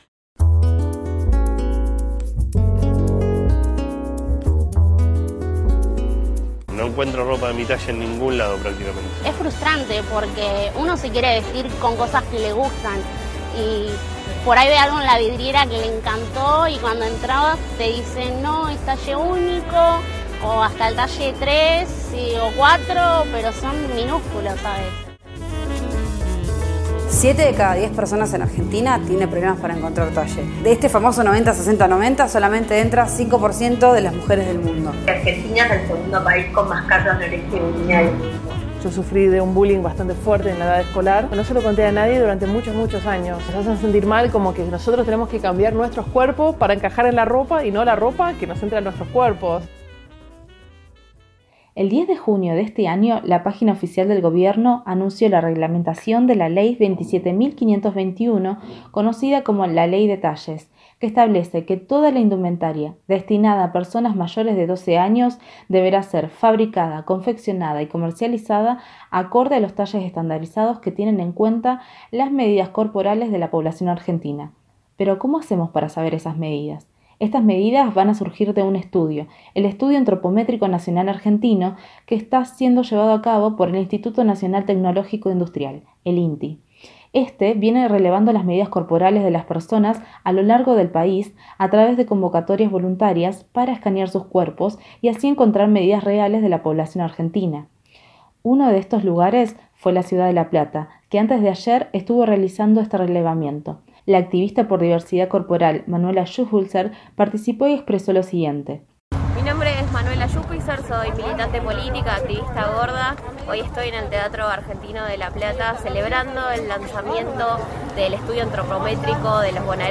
Por qué es importante. Las voces de quienes lo impulsan.